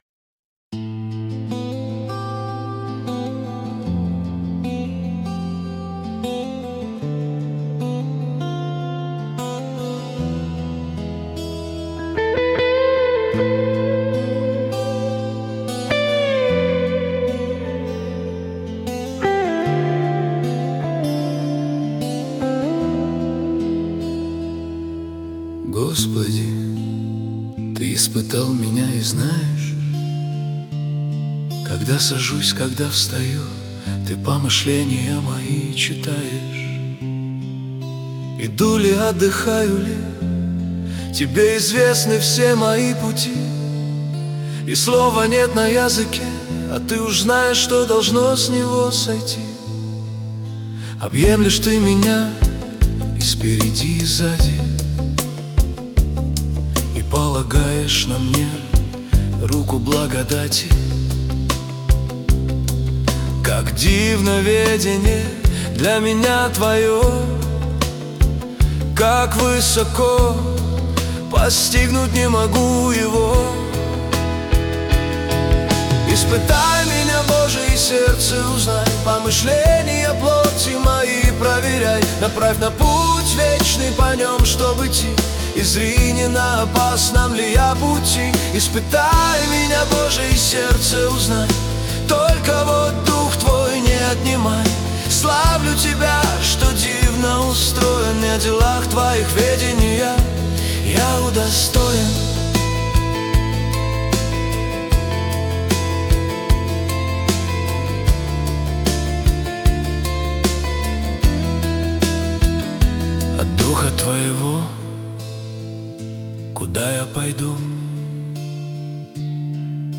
245 просмотров 634 прослушивания 87 скачиваний BPM: 76